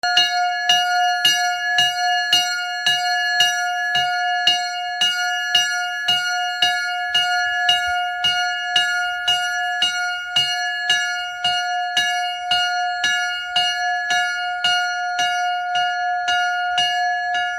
Am höchsten Punkt des Rundwegs verzückt eine Glocke mit ihren Klängen die Besucher der Fisser Gonde.
Glockenläuten
glockenlauten.mp3